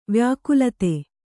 ♪ vyākulate